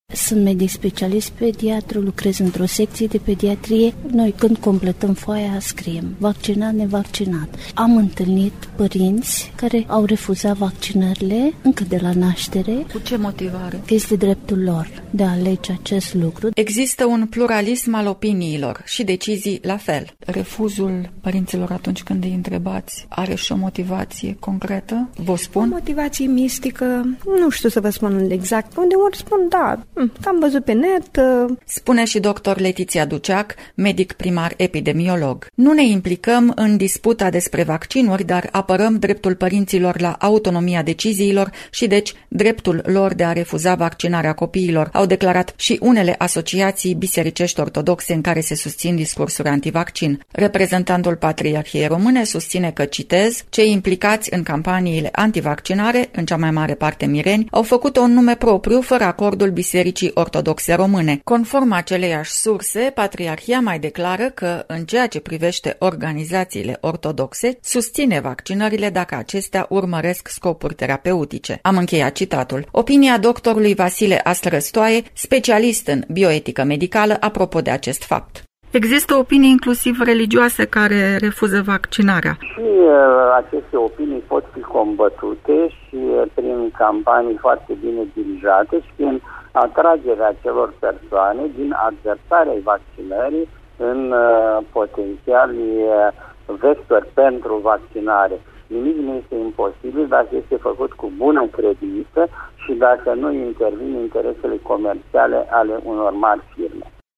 Un documentar despre istoria şi efectele vaccinurilor în Romania în cadrul campaniei „Vaccin Pentru viaţă”.
documentar.mp3